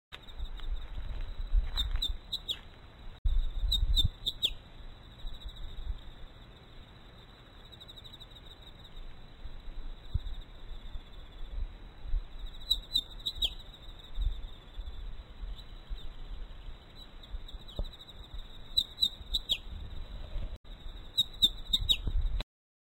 鳴 き 声：地鳴きはチッ、チッと鳴く。ちょっとホオジロの囀りに似た声でピーッピーッツーピーとかピーッピーッツクチュピー等と囀る。
鳴き声１